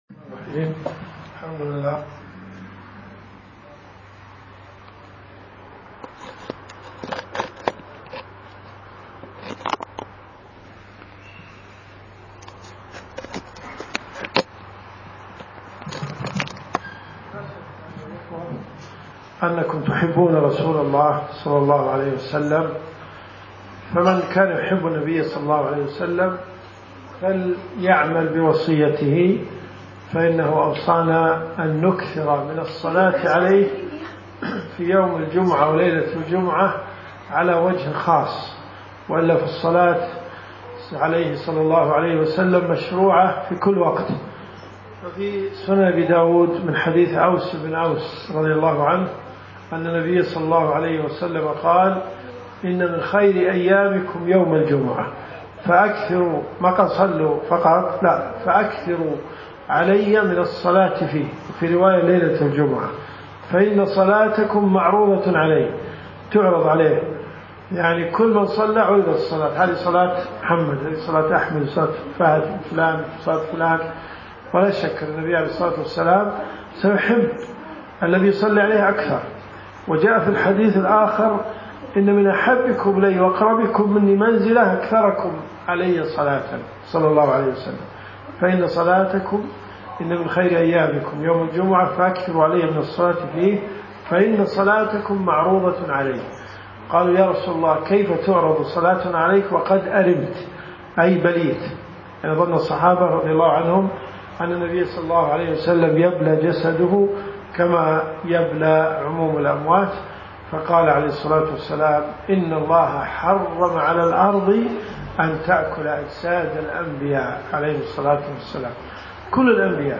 كلمات المساجد .
مسجد العجلان